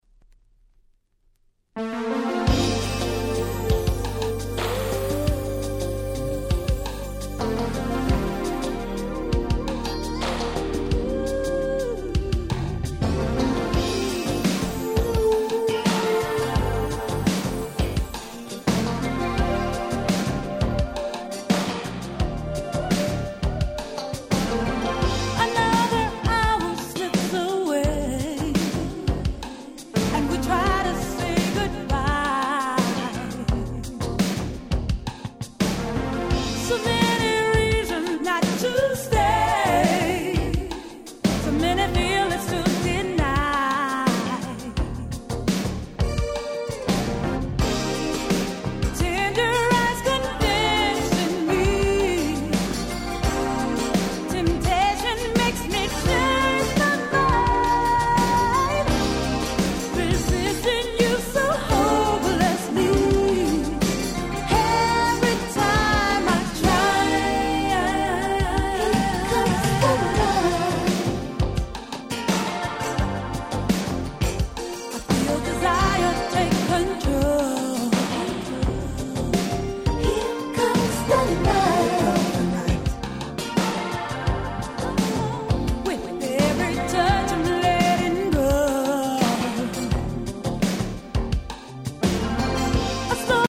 87' Smash Hit R&B LP !!